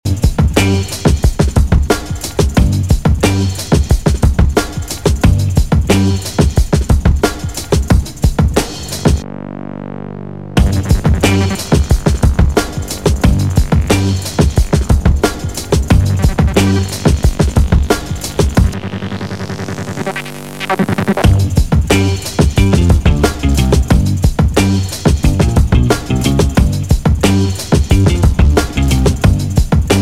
TOP >Vinyl >Drum & Bass / Jungle
Funky Instrumental